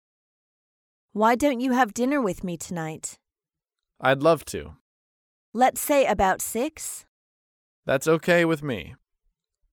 英语情景对话